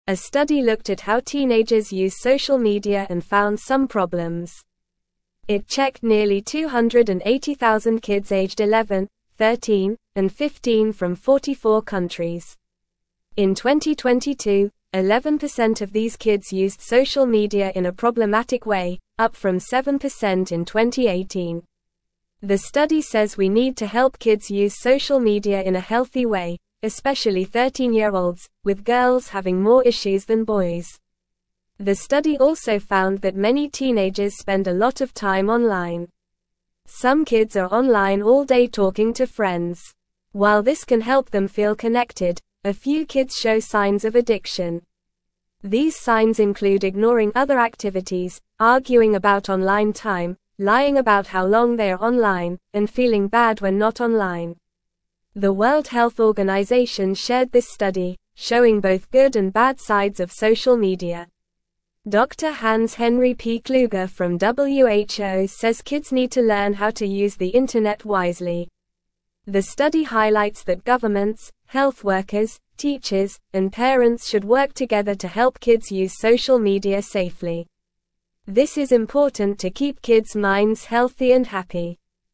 Normal
English-Newsroom-Lower-Intermediate-NORMAL-Reading-Kids-Using-Social-Media-More-Since-Pandemic-Started.mp3